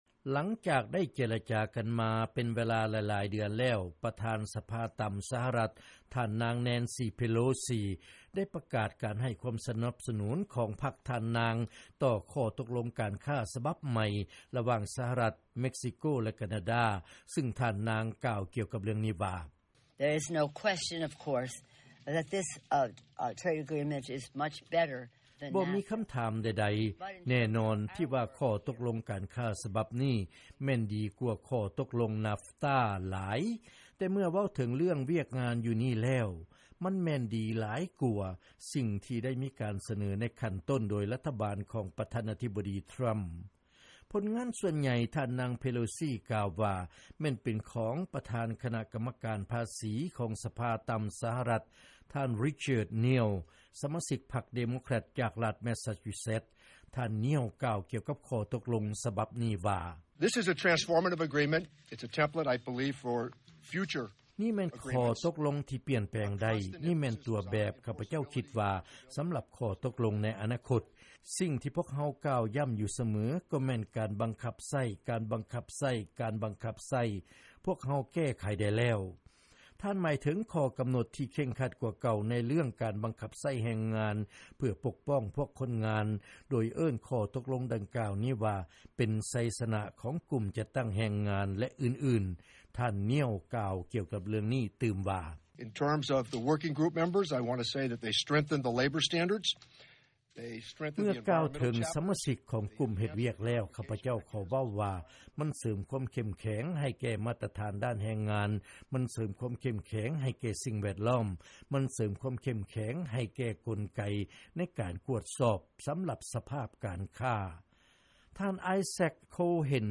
ເຊີນຟັງລາຍງານ ສະມາຊິກສະພາ ພັກເດໂມແຄຣັດ ປະກາດສະໜັບສະໜຸນ ຂໍ້ຕົກລົງການຄ້າ ໃນທະວີບອາເມຣິກາເໜືອ